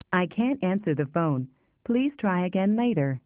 OnHoldMessage3.amr